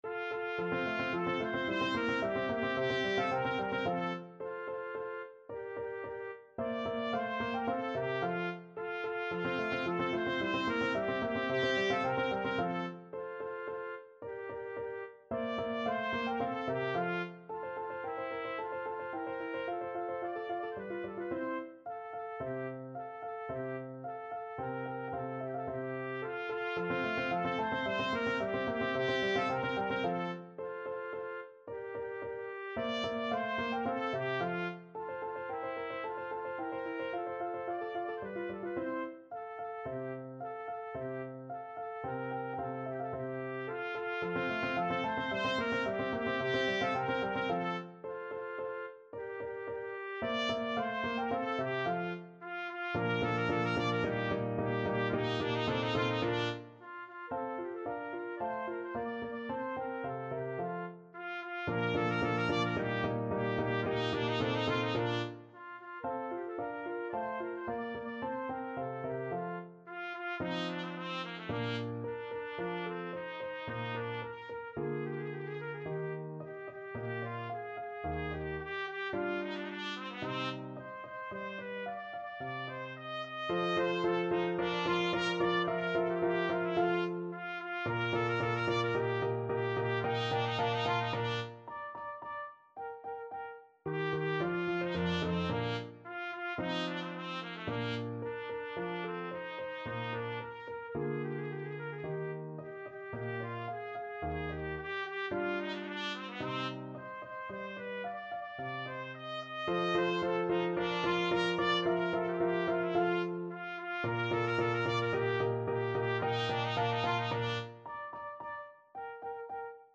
Trumpet version
2/4 (View more 2/4 Music)
Vivace assai =110 (View more music marked Vivace)
Classical (View more Classical Trumpet Music)